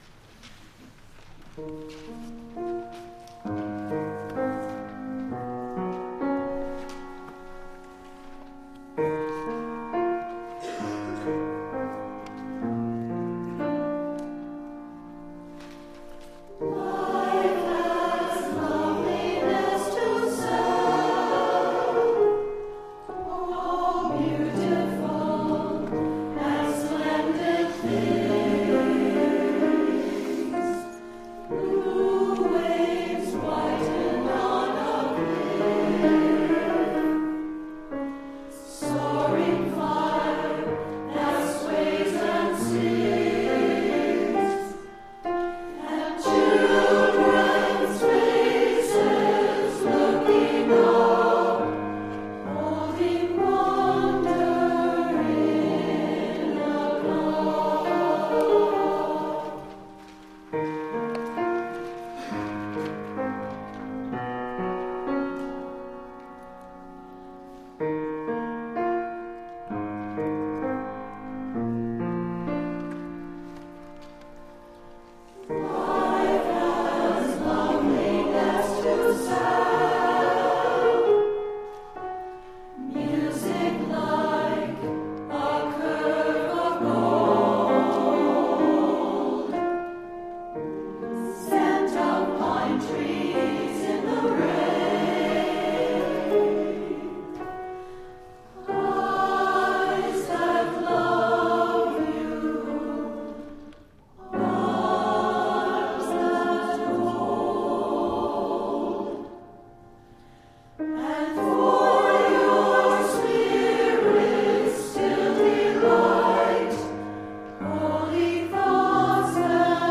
For SATB & Piano (Grade 4)